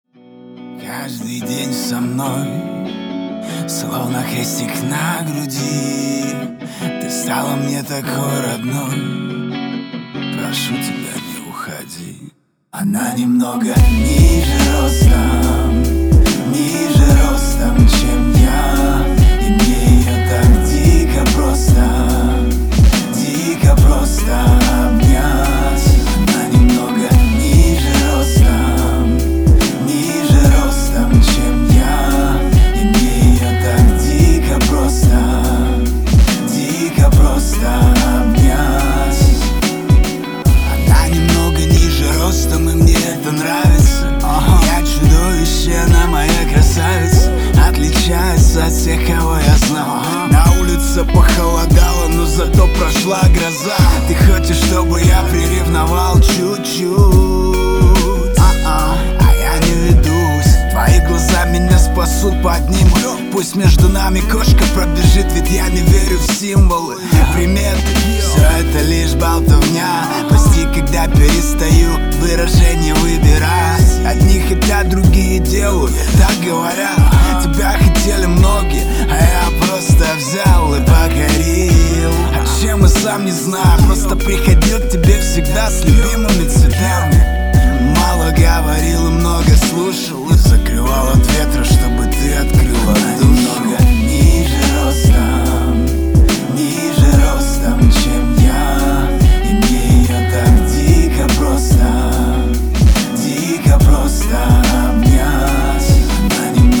• Качество: 320, Stereo
громкие
лирика
Хип-хоп
романтичные